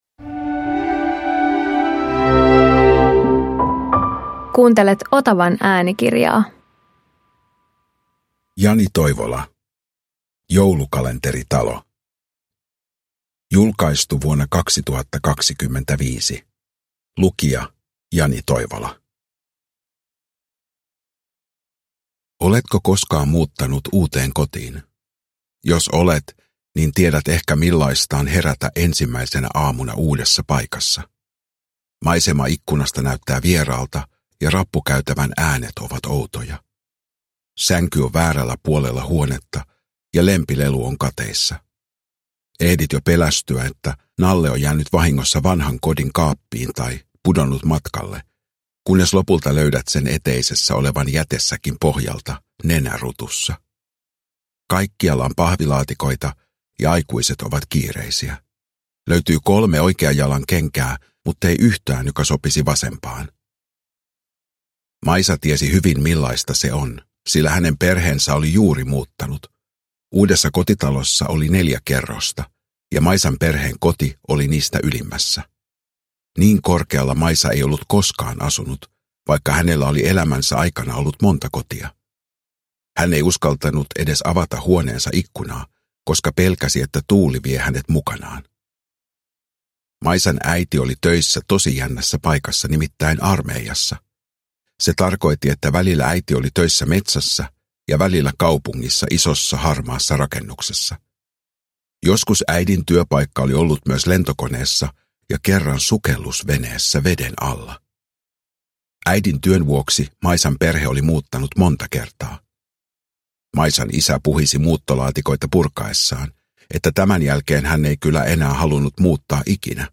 Joulukalenteritalo – Ljudbok
Uppläsare: Jani Toivola